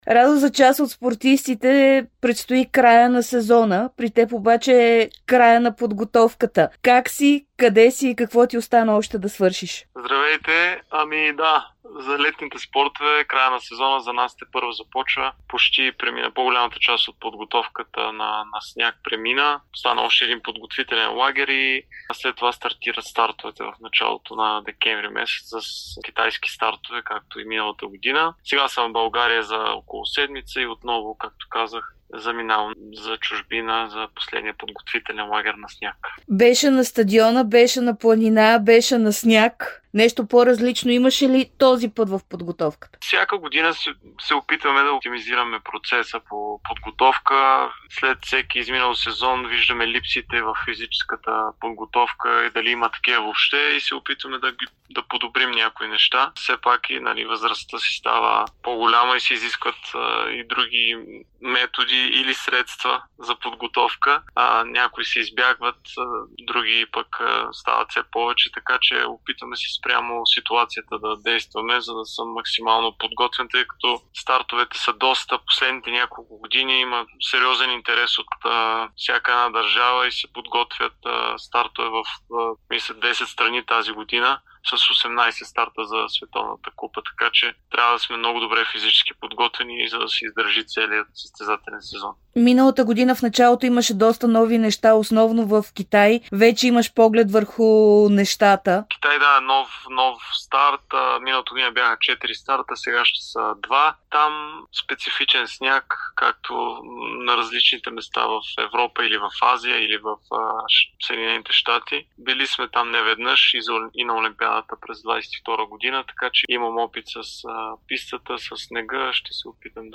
Един от най-добрите български състезатели по сноуборд Радослав Янков говори пред Дарик преди началото на неговия юбилеен 20-и сезон.